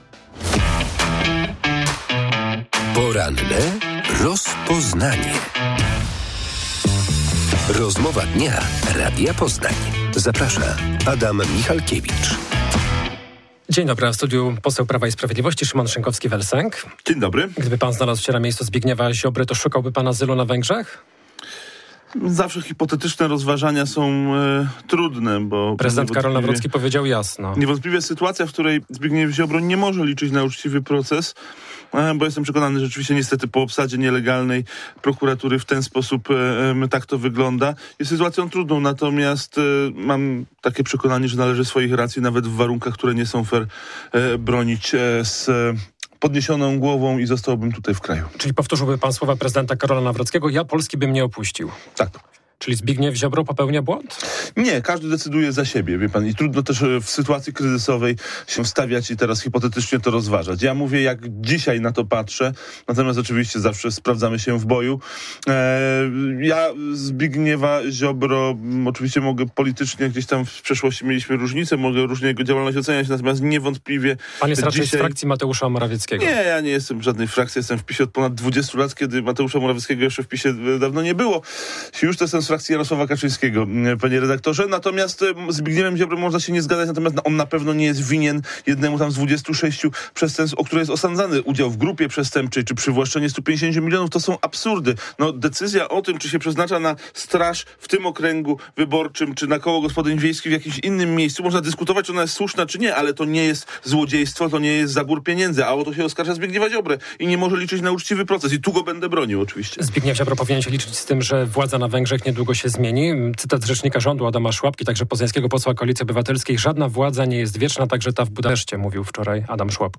W porannej rozmowie Radia Poznań Szymon Szynkowski vel Sęk przyznał, że działacze mocno myślą o tym, kto będzie startował w kolejnych wyborach.